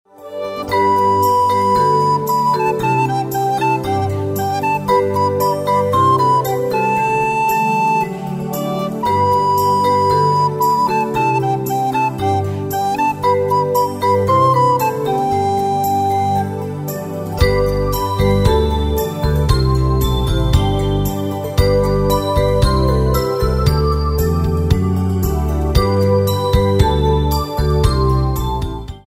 Ein Weihnachts-Minimusical